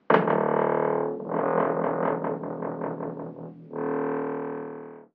Bisagra de una puerta al abrir
bisagra
Sonidos: Hogar